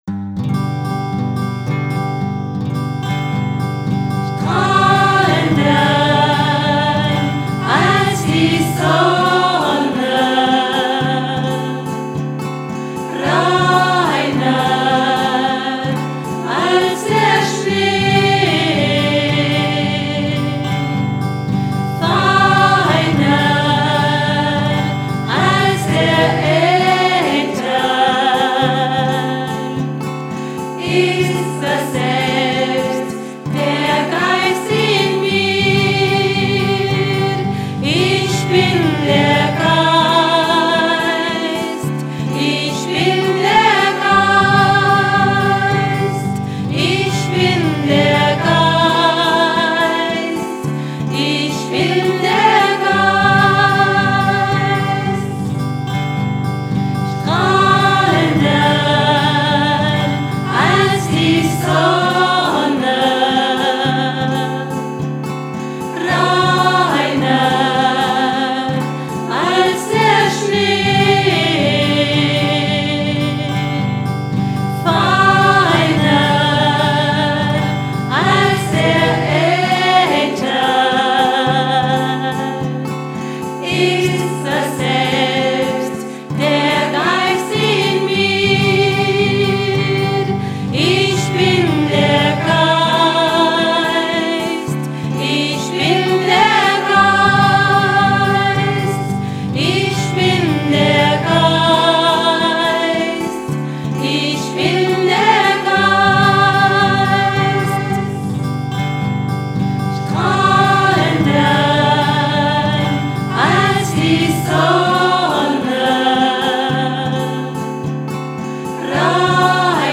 Themenwelt Kunst / Musik / Theater Musik Pop / Rock